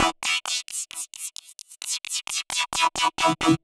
Index of /musicradar/uk-garage-samples/132bpm Lines n Loops/Synths